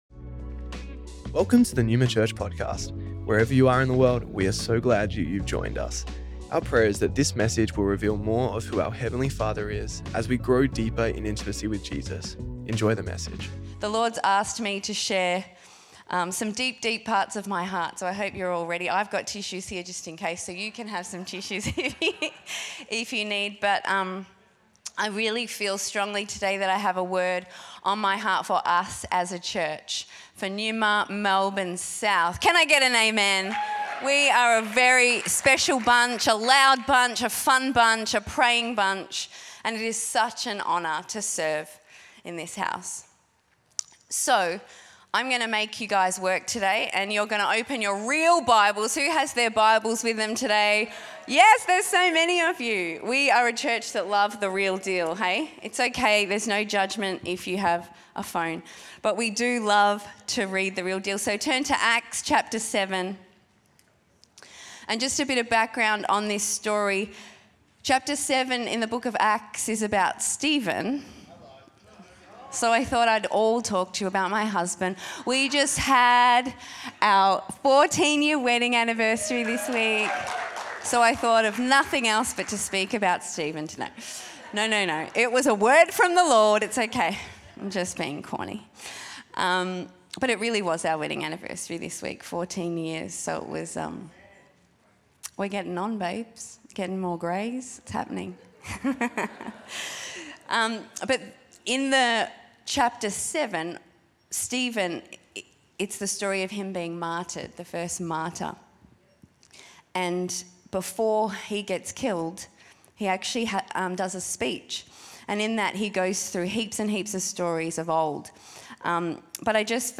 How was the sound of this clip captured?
Originally Recorded at the 10AM Service on Sunday 3rd November 2024&nbsp